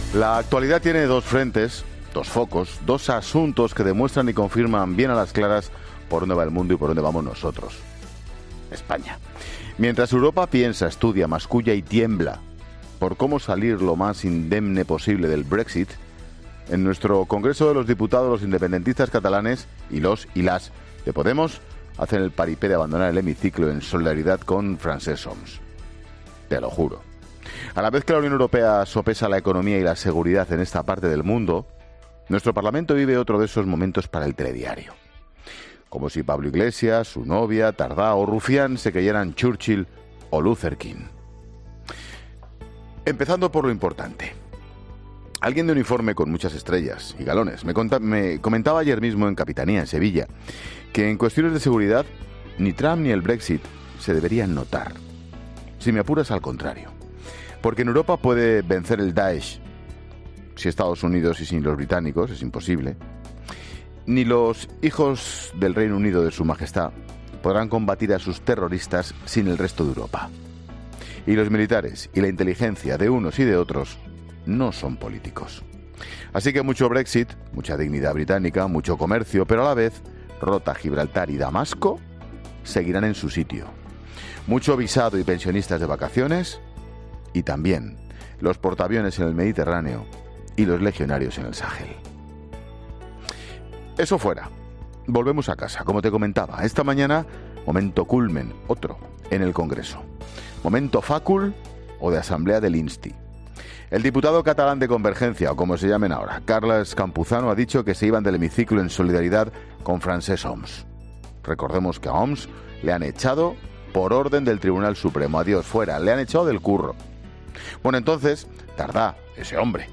AUDIO: Monólogo 16h.